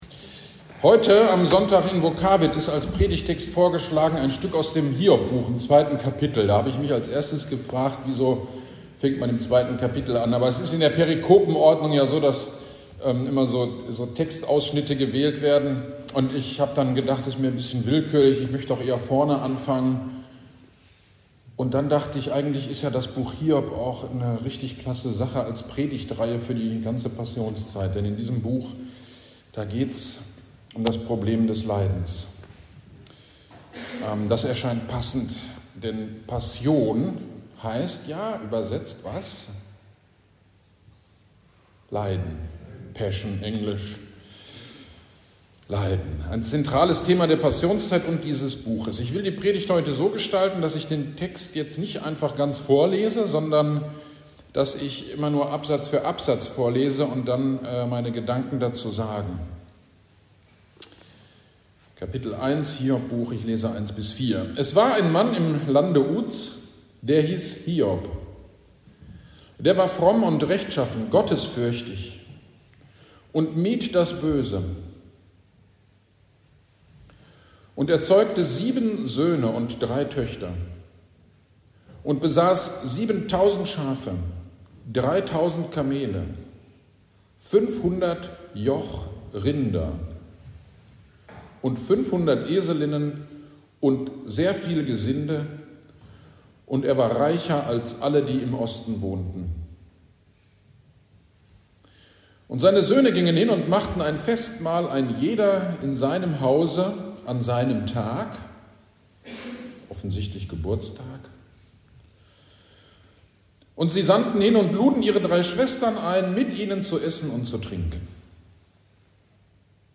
Gottesdienst am 26.02.23 Predigt zu Hiob 1 - Kirchgemeinde Pölzig